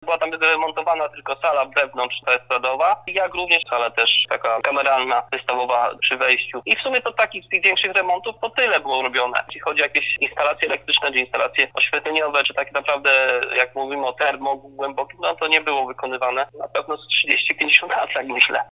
Burmistrz Leszek Kopeć zaznacza, że prace są konieczne, bo budynek nie spełnia żadnych kryteriów izolacji termicznej.